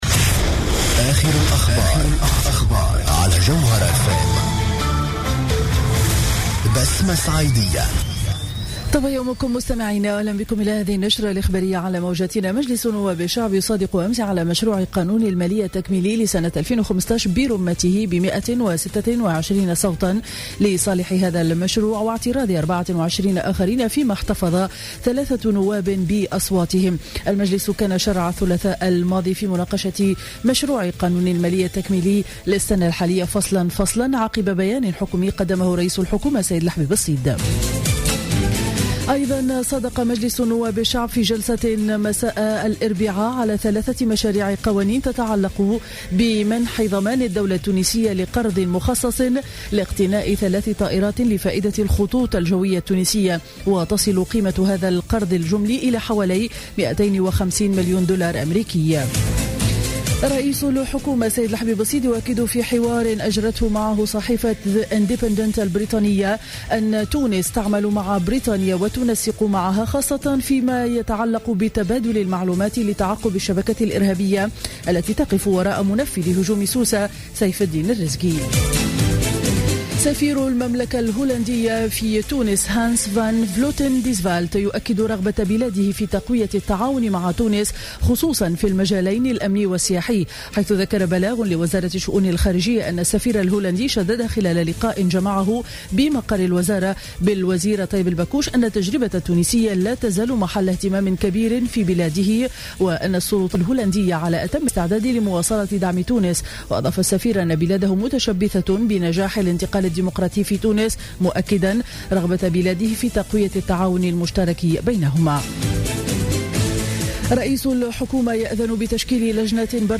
نشرة أخبار السابعة صباحا ليوم الخميس 06 أوت 2015